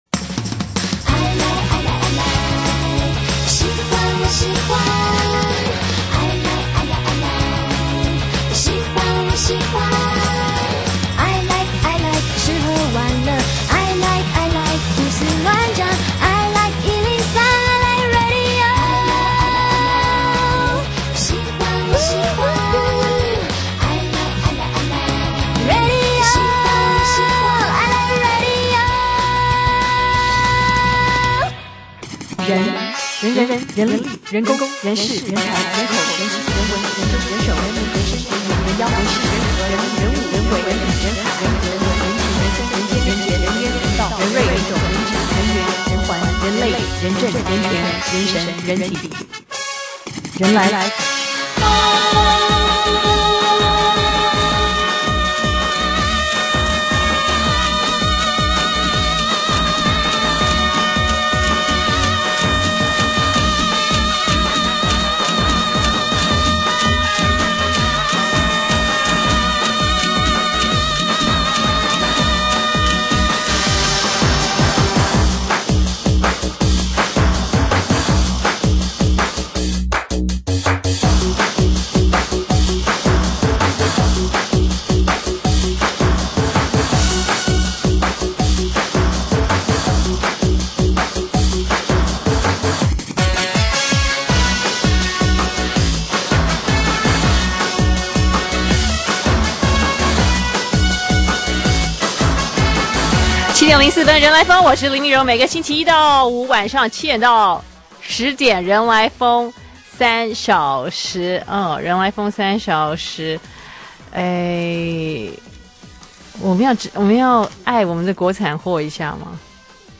2012-10-16星期二（高雄電影節專訪）